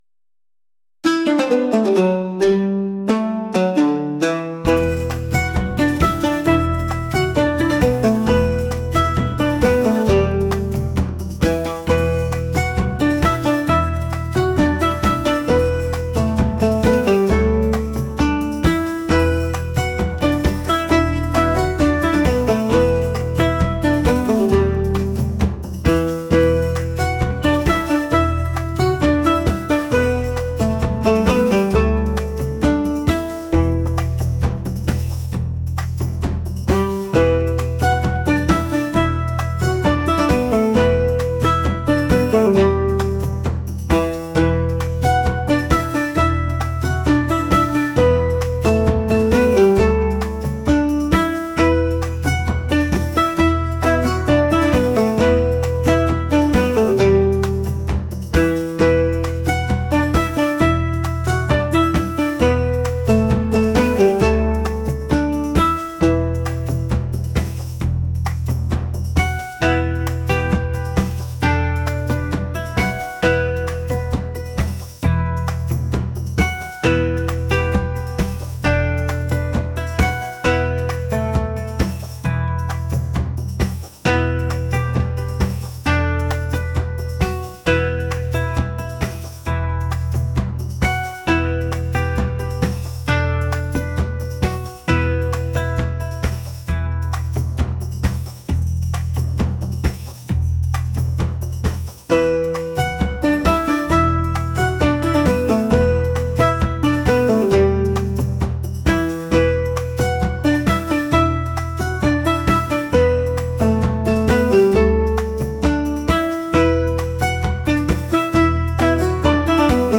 traditional | rhythmic | world